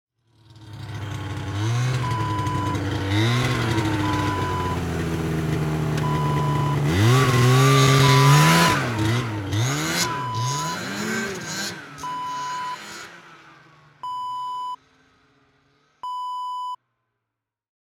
Snowmobile: Take Off Wav Sound Effect #1
Description: Snowmobile arrives and takes off
Properties: 48.000 kHz 24-bit Stereo
A beep sound is embedded in the audio preview file but it is not present in the high resolution downloadable wav file.
snowmobile-take-off-preview-1.mp3